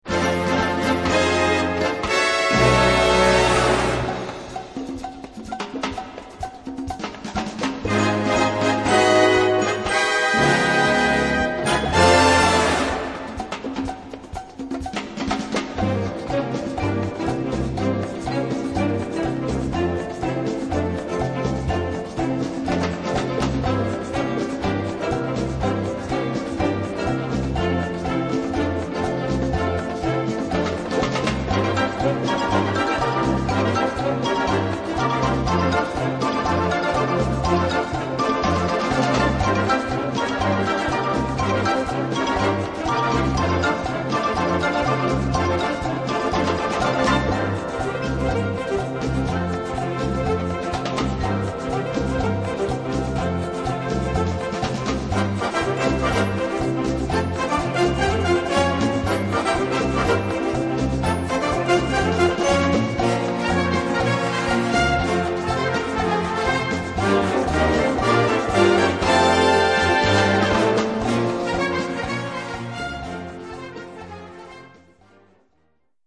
Gattung: Mambo
Besetzung: Blasorchester
ist mit ihren Swing-Rhythmen